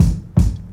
Kick 10.wav